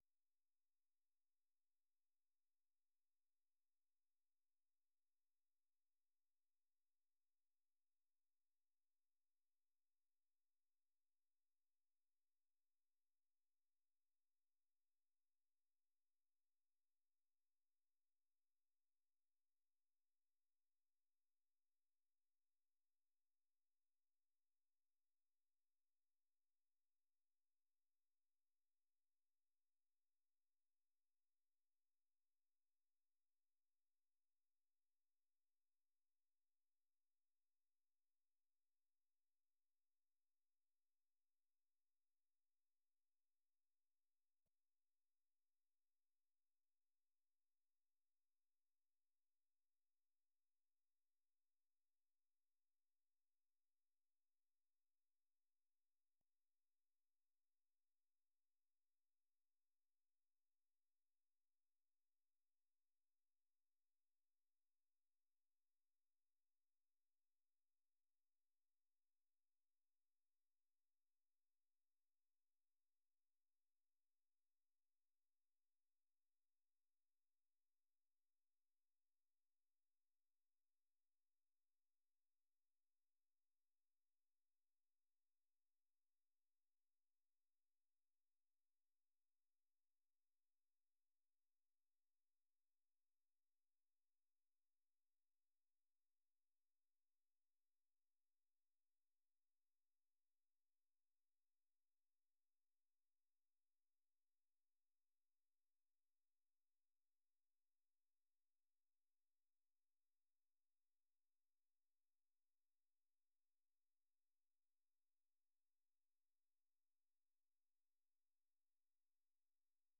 VOA 한국어 방송의 일요일 오전 프로그램 1부입니다.